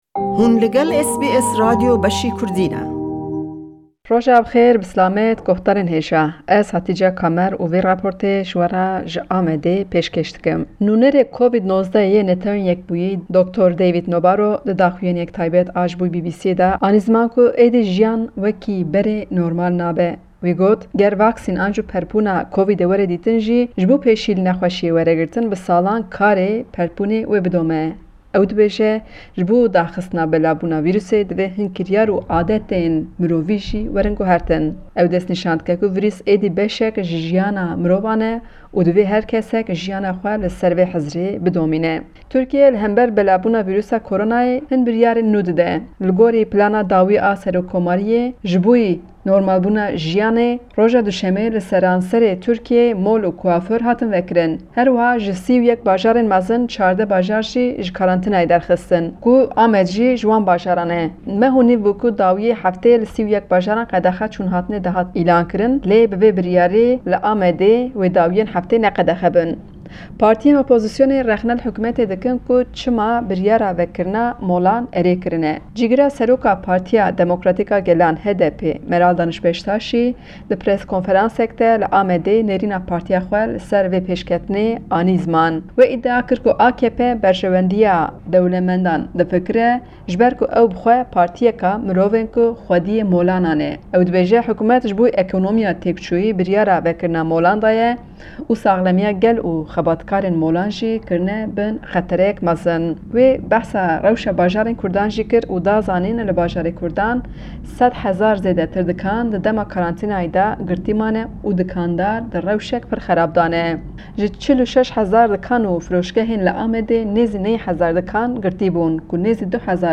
Raporta vê heftê ji Amedê li ser cejna zimanê Kurdî û rewşa dawî ya Koronayê, herwisa rewşa karkerên zeviyan ye.